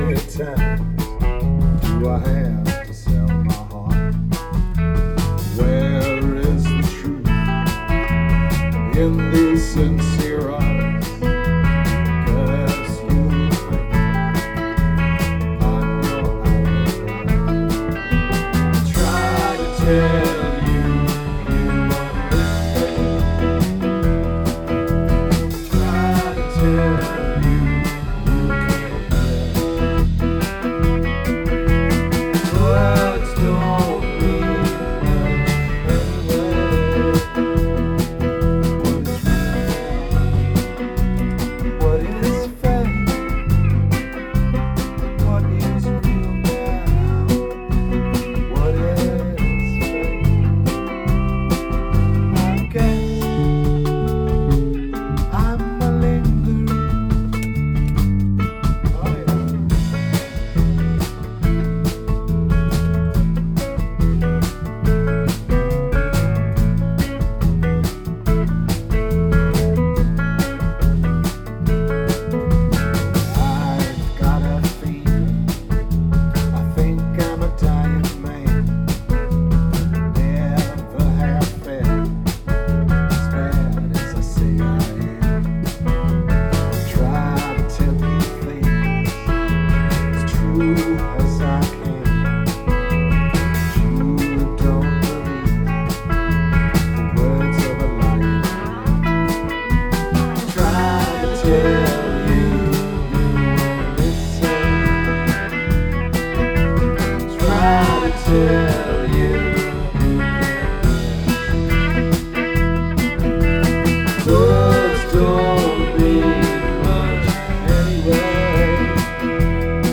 Rehearsals 30.7.2013